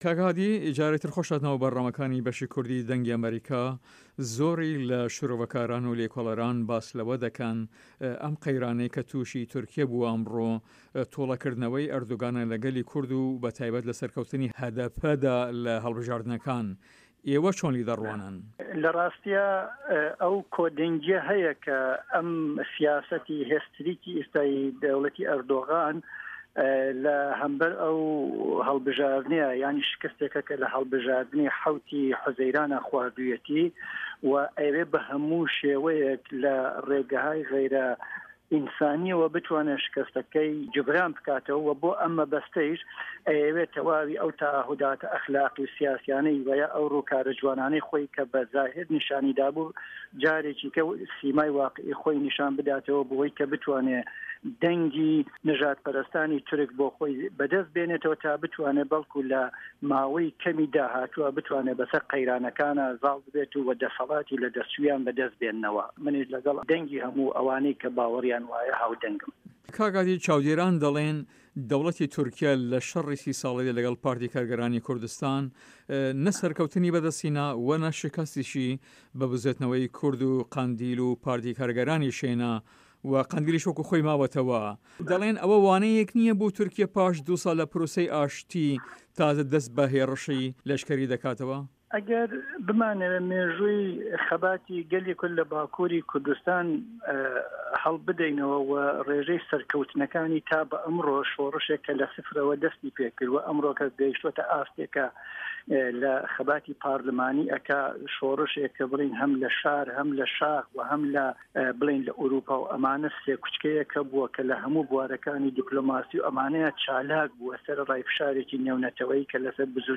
له‌ هه‌ڤپه‌یڤینێکدا له‌گه‌ڵ به‌شی کوردی ده‌نگی ئه‌مه‌ریکا